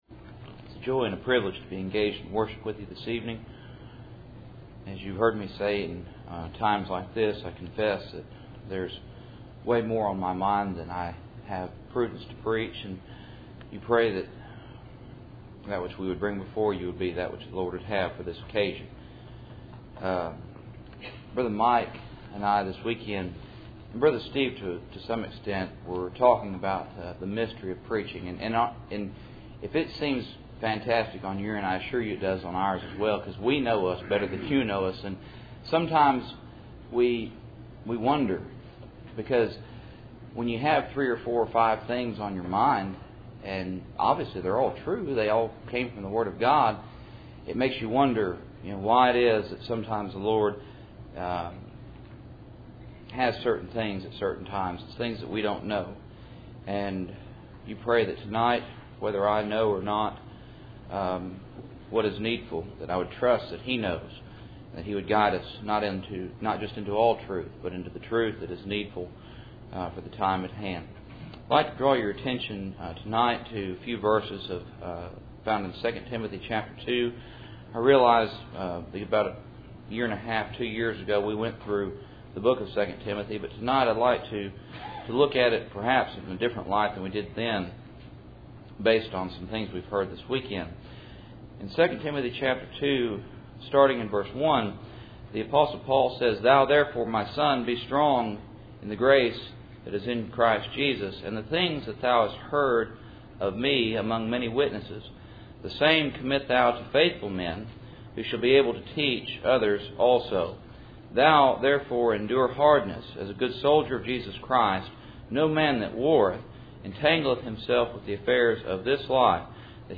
Passage: 2 Timothy 2:1-5 Service Type: Cool Springs PBC Sunday Evening %todo_render% « Reasonable Service Predestinated